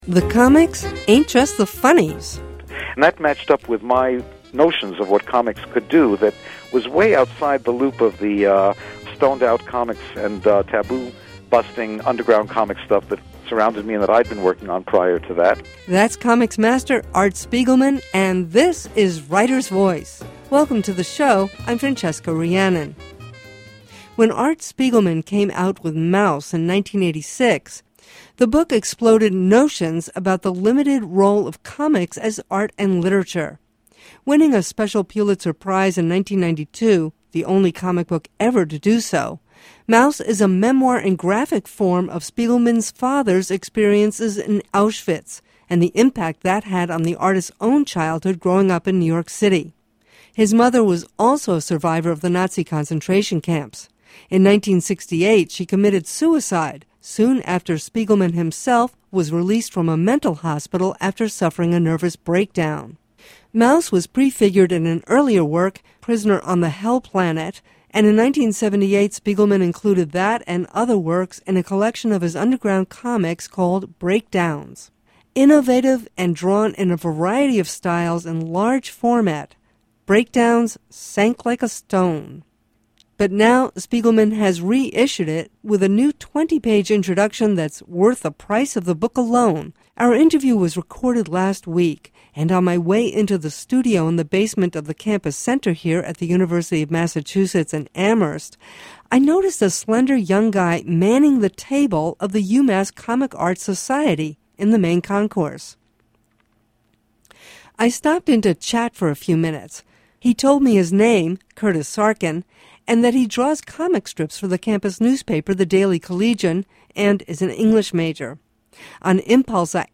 Also, investigative journalist Greg Palast talks about the comic book he produced with Robert F. Kennedy, Jr., STEAL BACK YOUR VOTE!.